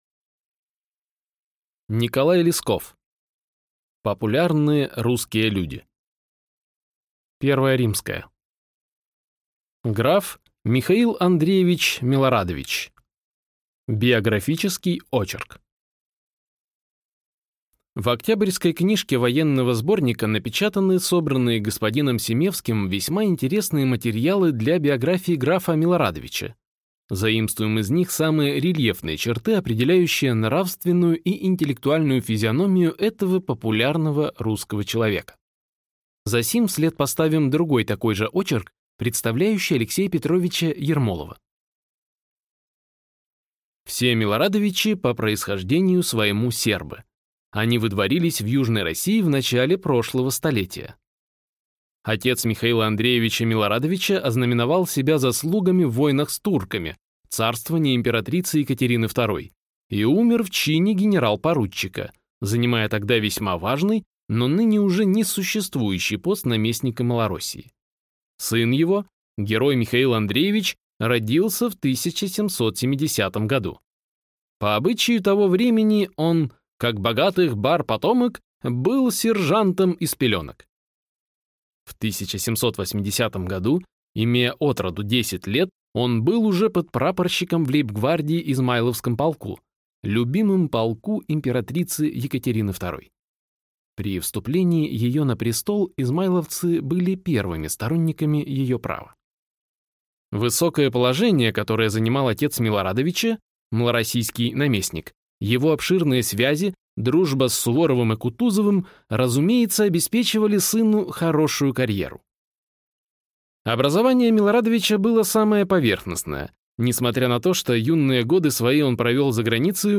Аудиокнига Популярные русские люди | Библиотека аудиокниг